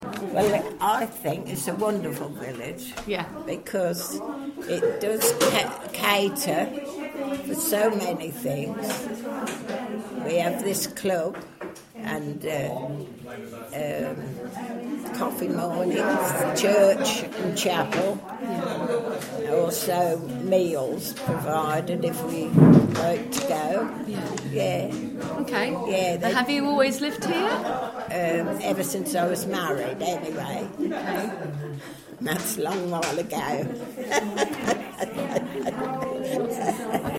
Card players reflect on what its like living in the area